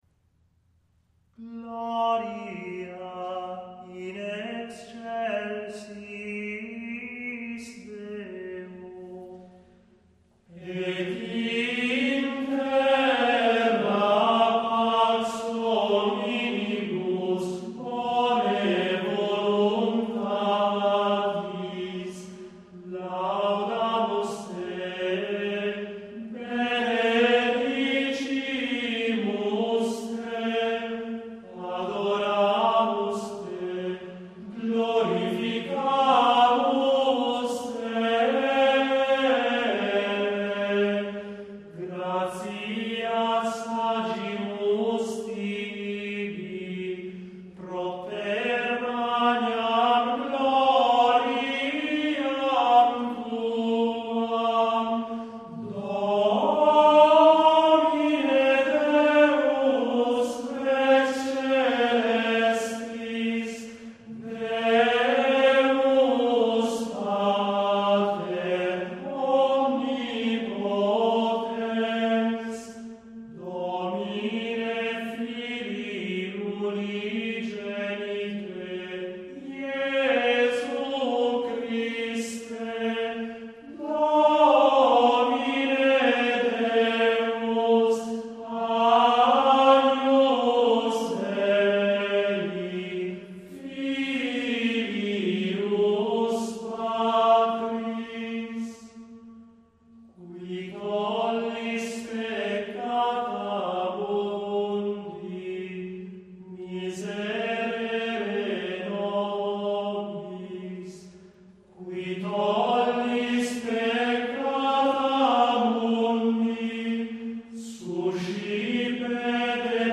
Gloria in excelsis I — Cantori gregoriani | dir.